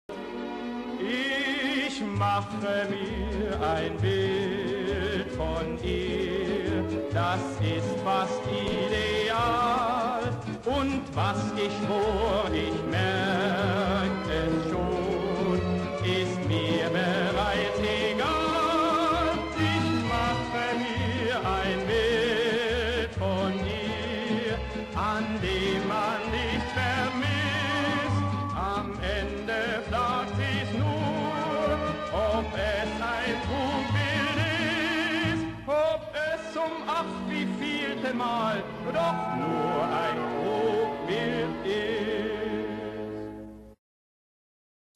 Musikalisches Lustspiel in sechs Bildern